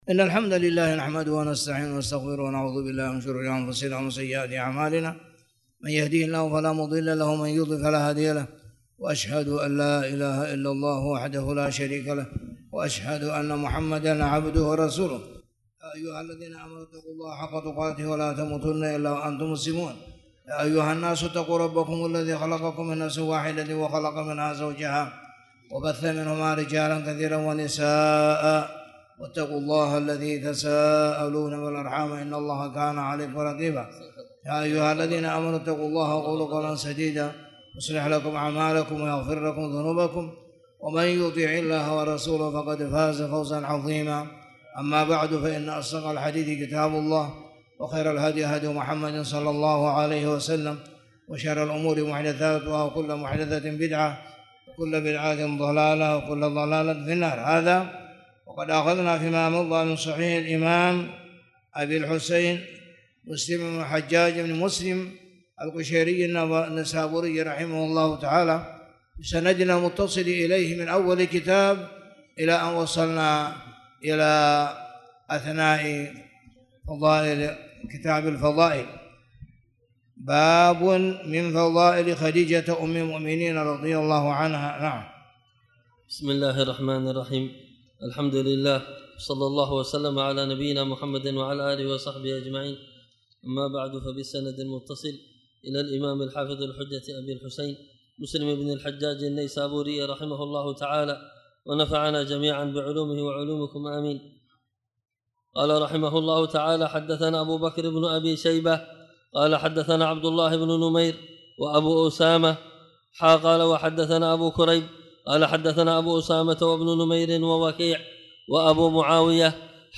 تاريخ النشر ١٤ صفر ١٤٣٨ هـ المكان: المسجد الحرام الشيخ